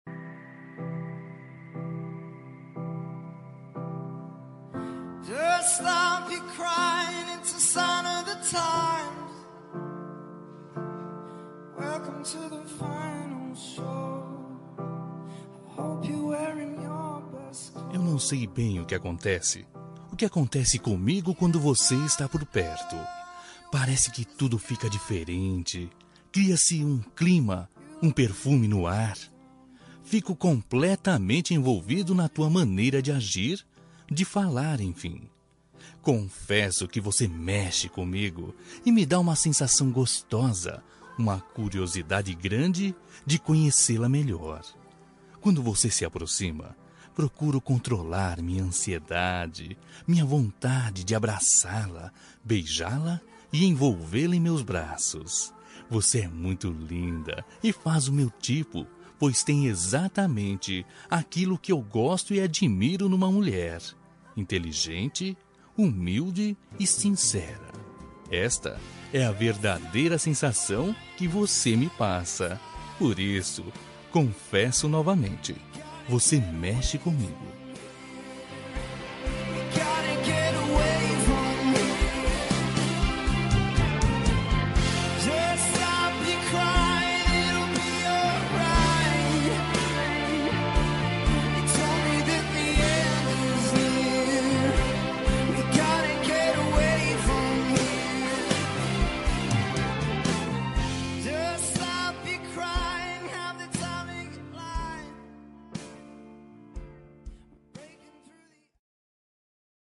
Telemensagem Paquera – Voz Masculina – Cód: 051588